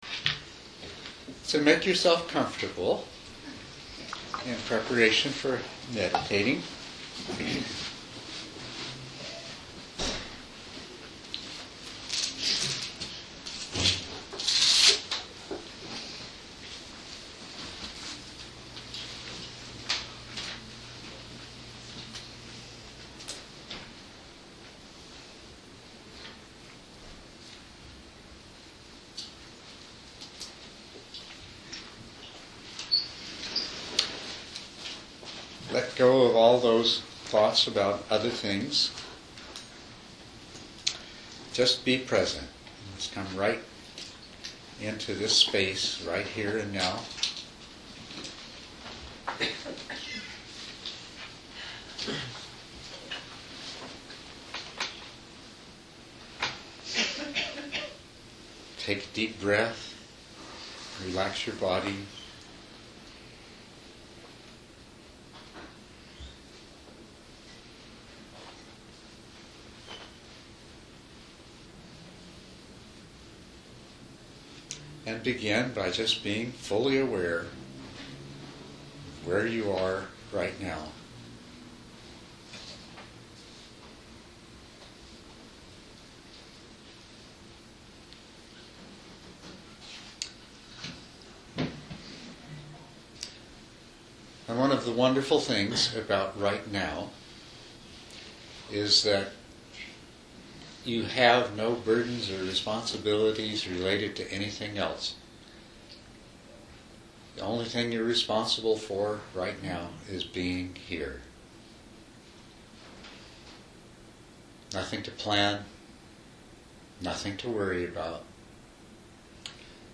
Through a series of guided meditations, participants have an opportunity to learn about and experience Jhana first hand.
jhanas-meditation-and-discussion-sat am.mp3